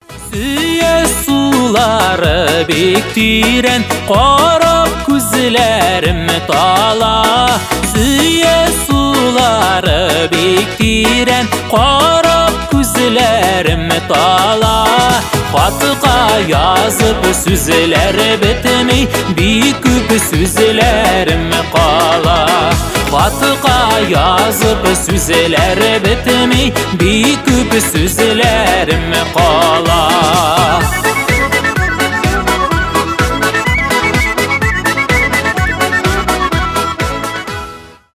поп , татарские